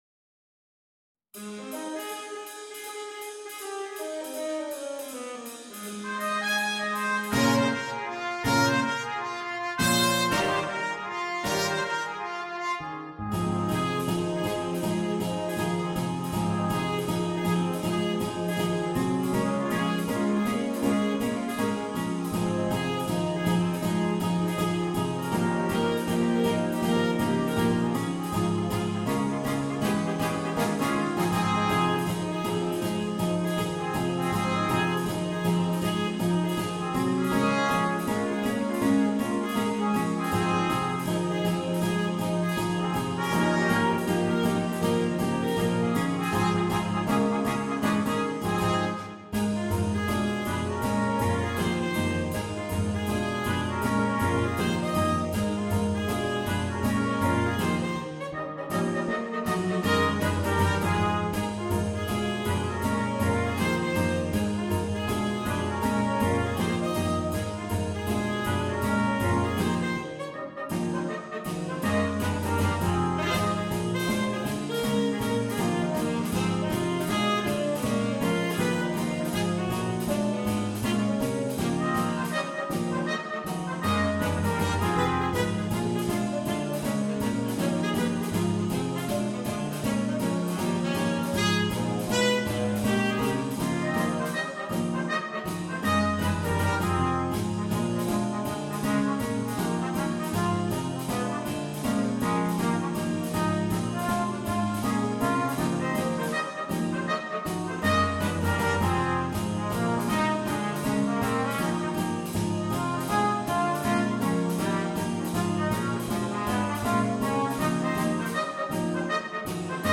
на смешанный состав